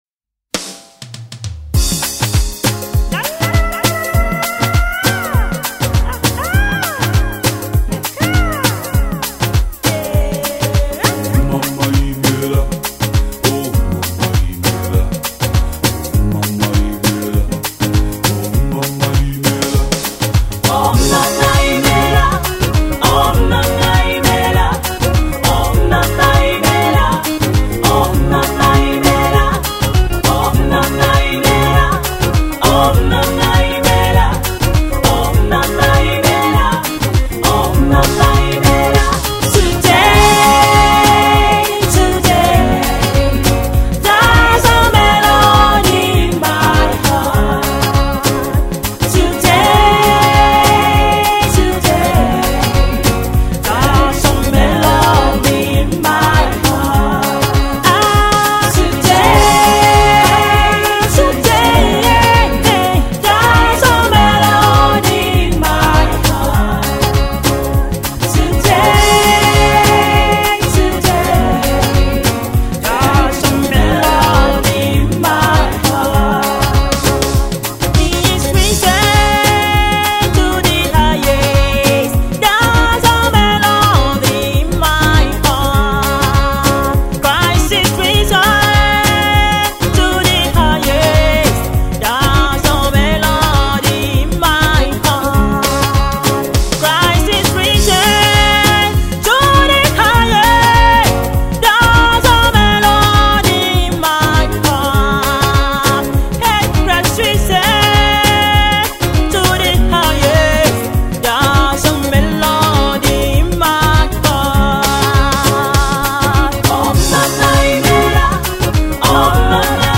African beat
Afro-pop single